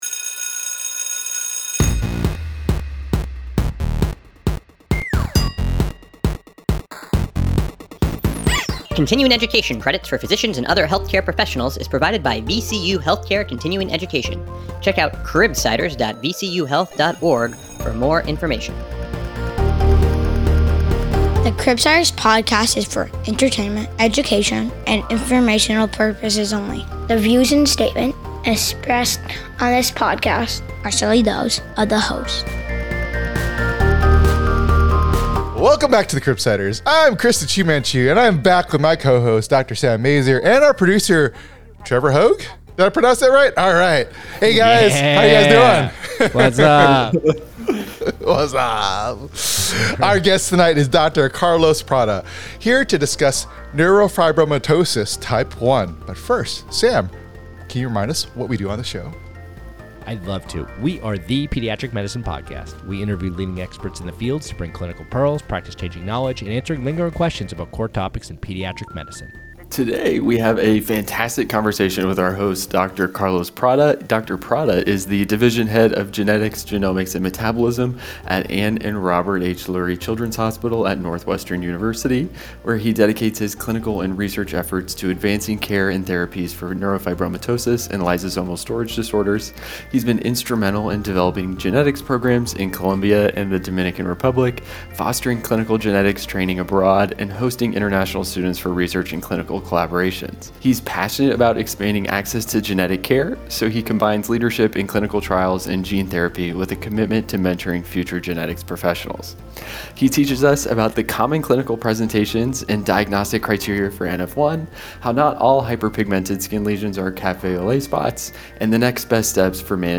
Grab a cafe au lait and settle in for a nerve-rackingly good conversation